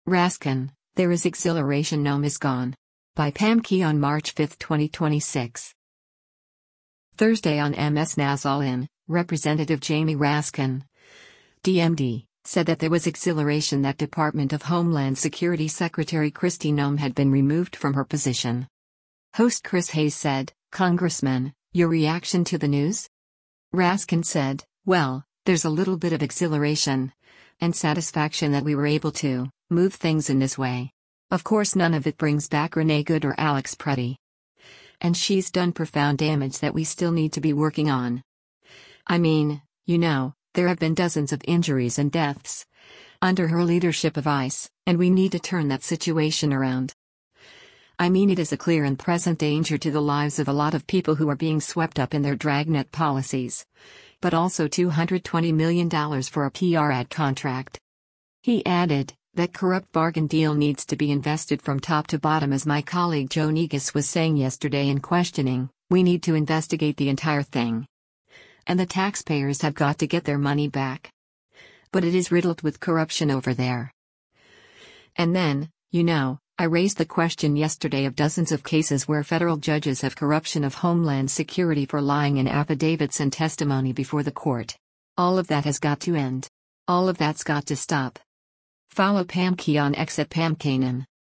Thursday on MS NOW’s “All In,” Rep. Jamie Raskin (D-MD) said that there was “exhilaration” that Department of Homeland Security Secretary Kristi Noem had been removed from her position.